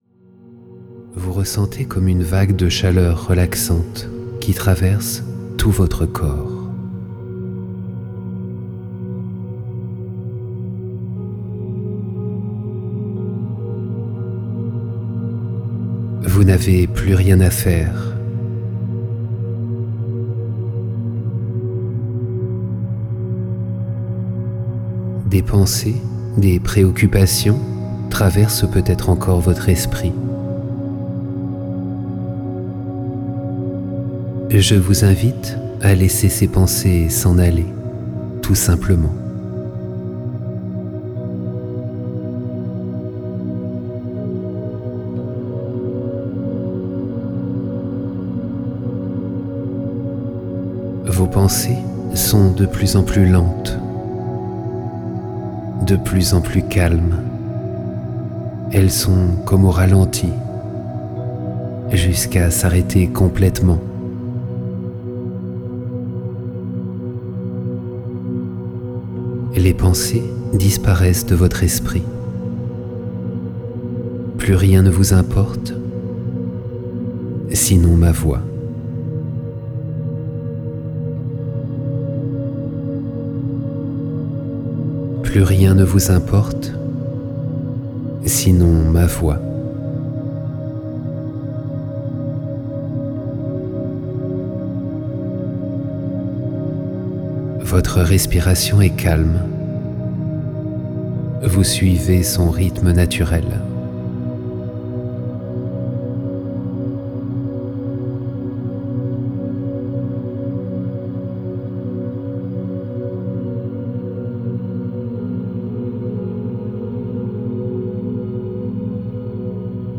Relaxation profonde guidée pour l'activation du pouvoir d'autoguérison